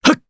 Clause_ice-Vox_Jump_jp_b.wav